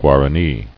[Gua·ra·ní]